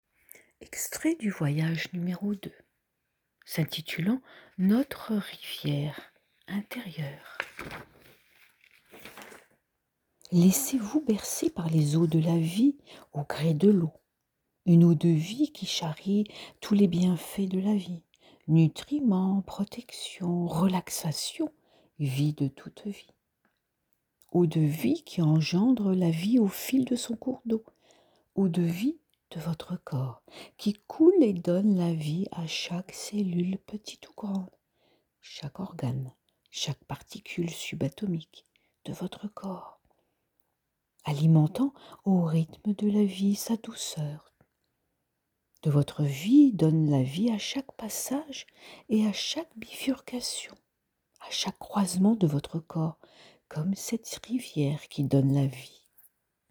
2ème voyage méditatif - intitulé : Votre rivière intérieure